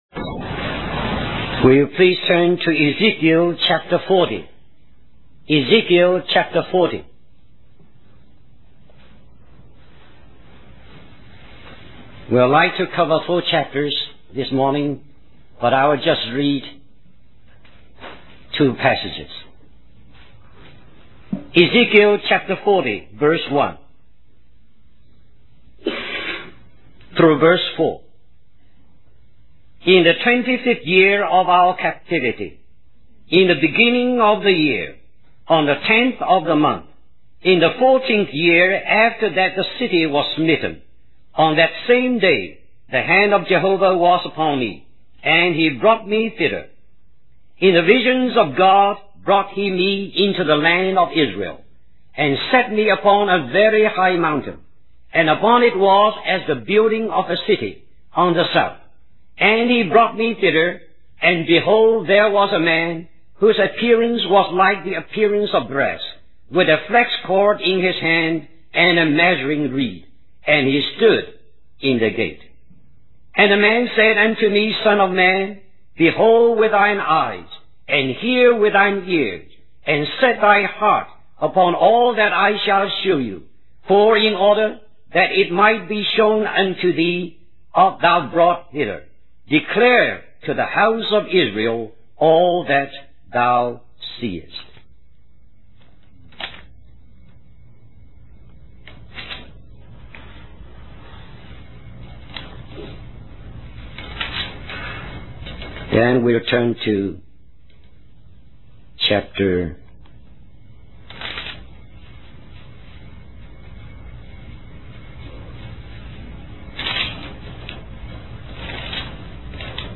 1980 Christian Family Conference Stream or download mp3 Summary In Ezekiel chapters 40 and 43, the prophet Ezekiel receives a vision of the house of God, emphasizing God's desire for a holy dwelling among His people.